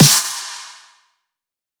Snare 4 (Scary bolly dub).wav